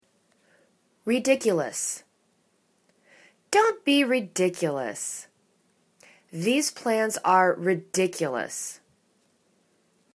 ri.dic.u.lous  /ri'dikyәlәs/ adj